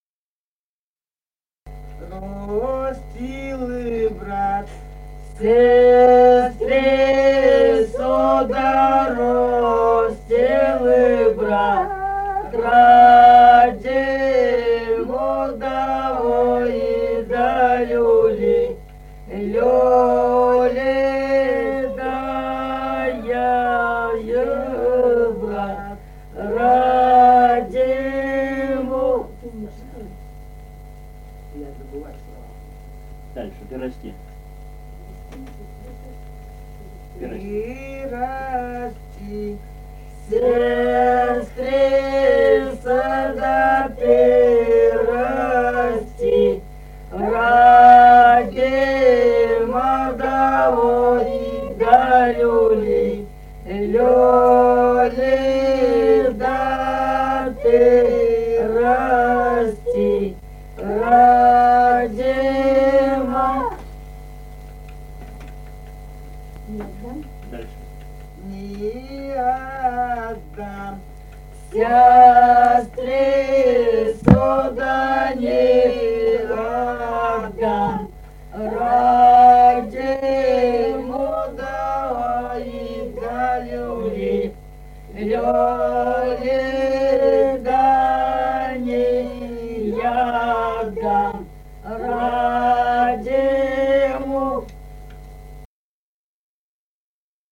Русски песни Алтайского Беловодья 2 «Ро́стил брат сестрицу», «лужошная».
Республика Казахстан, Восточно-Казахстанская обл., Катон-Карагайский р-н, с. Фыкалка, июль 1978.